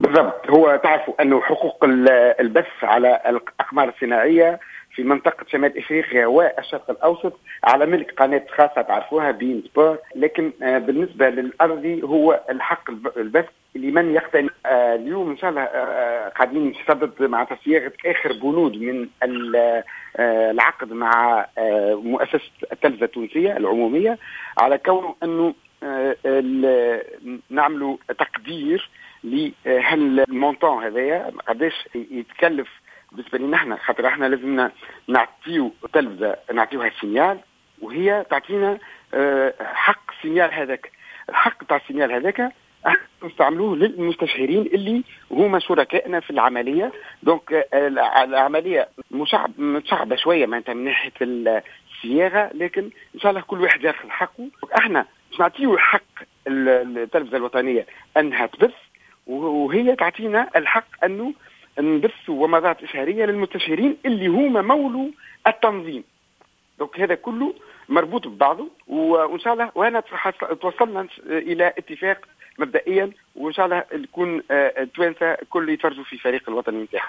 تصريح لجوهرة أف أم